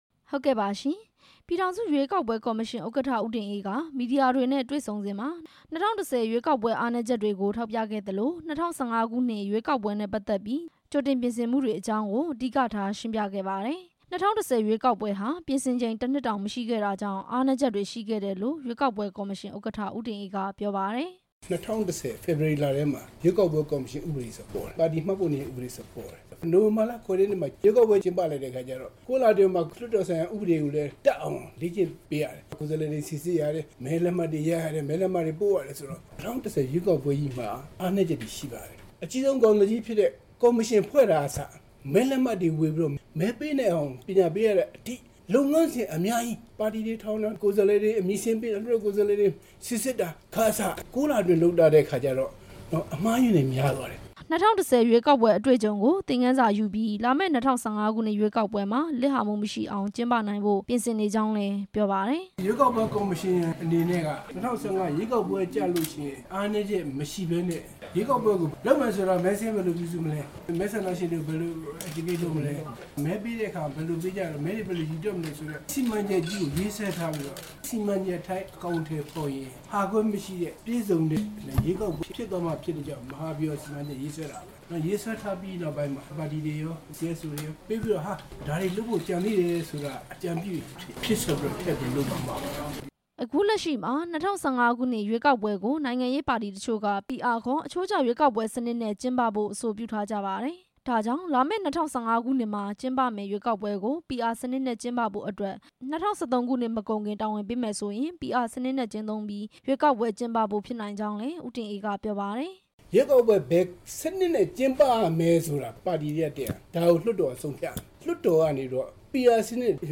ရွေးကောက်ပွဲကော်မရှင် ဥက္ကဌ ဦးတင်အေးရဲ့ ပြောကြားချက်
ရန်ကုန်မြို့ အင်းလျားလိတ်ဟိုတယ်မှာ ဒီနေ့မနက်ပိုင်းကကျင်းပတဲ့ "နိုင်ငံရေးပါတီတွေနဲ့ မီဒီယာနှီးနှောဖလှယ်ပွဲ" ကို ပြည်ထောင်စုရွေးကောက်ပွဲ ကော်မရှင်ဥက္ကဌ တက်ရောက်ခဲ့ပြီး မီဒီယာတွေနဲ့ တွေ့ဆုံစဉ် ပြောခဲ့တာပါ။